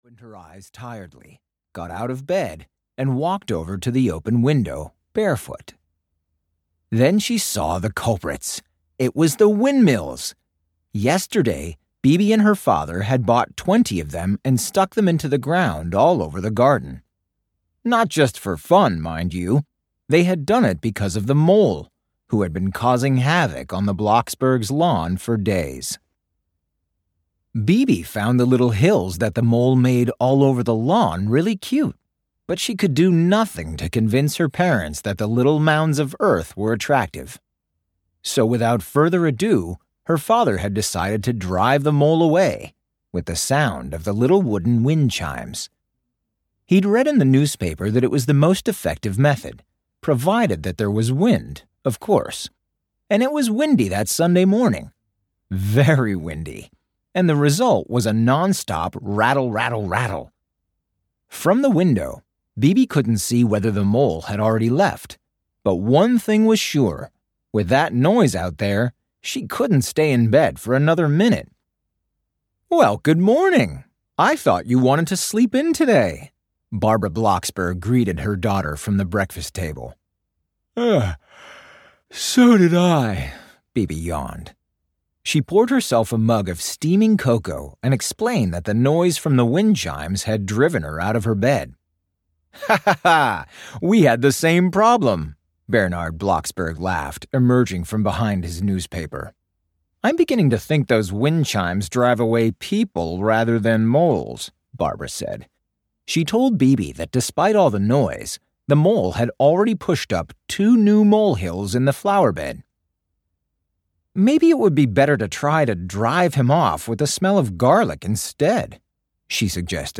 Audio knihaBibi Blocksberg - What's Wrong with Daddy? (EN)
Ukázka z knihy